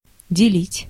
Ääntäminen
US : IPA : [ˈsplɪt]